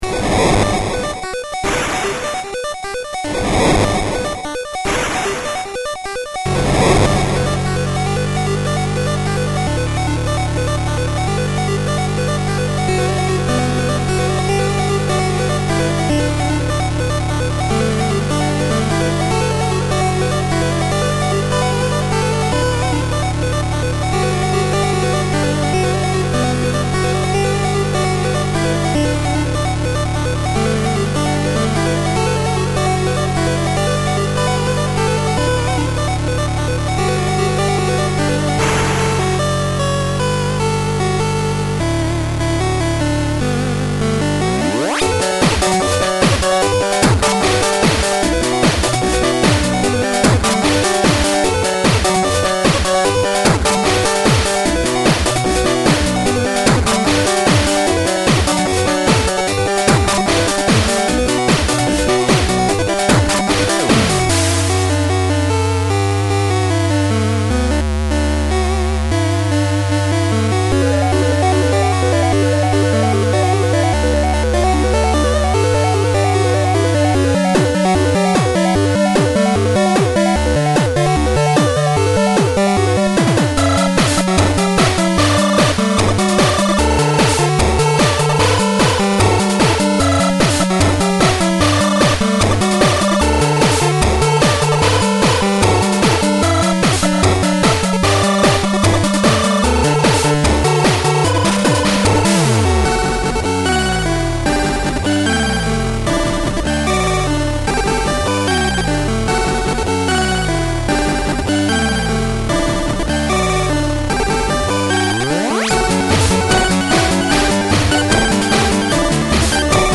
LSDJ on Emulator. enjoy